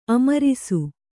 ♪ amarisu